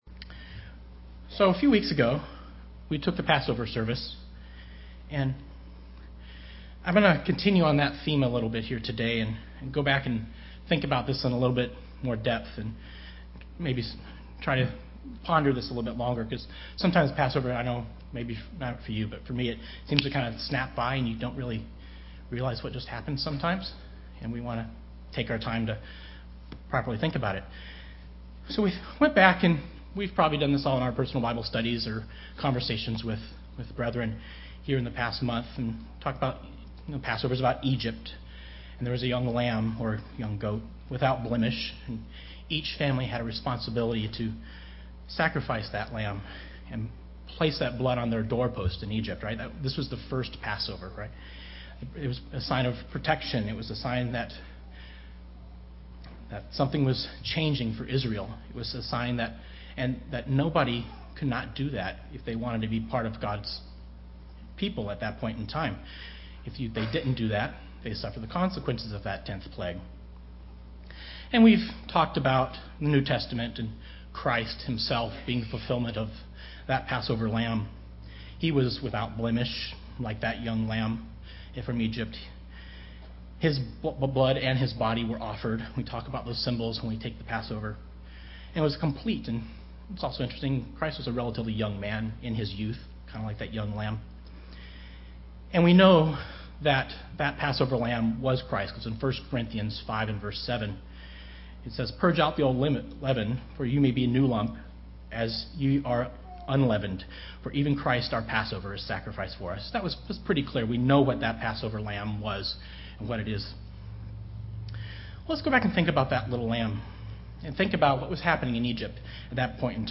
Given in Seattle, WA
UCG Sermon Studying the bible?